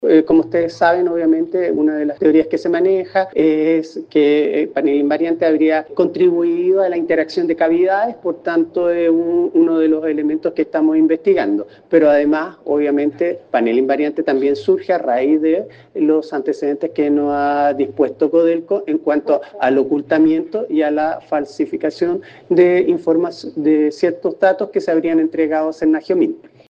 Así lo informó el Fiscal Regional de O’Hggins, Aquiles Cubillos